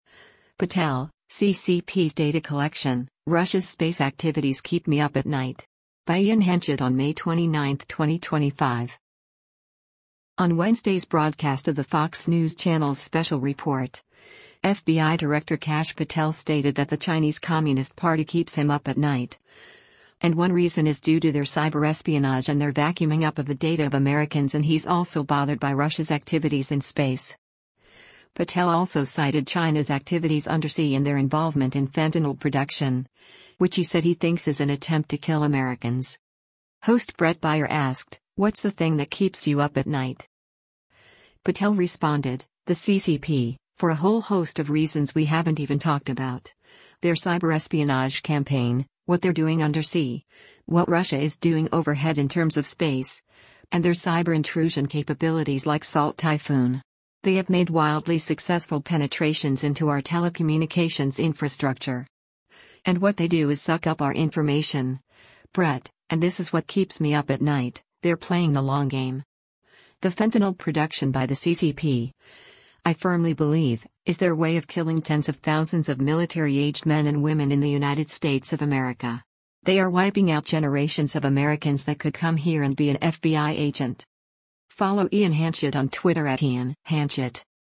On Wednesday’s broadcast of the Fox News Channel’s “Special Report,” FBI Director Kash Patel stated that the Chinese Communist Party keeps him up at night, and one reason is due to their cyberespionage and their vacuuming up of the data of Americans and he’s also bothered by Russia’s activities in space. Patel also cited China’s activities undersea and their involvement in fentanyl production, which he said he thinks is an attempt to kill Americans.
Host Bret Baier asked, “What’s the thing that keeps you up at night?”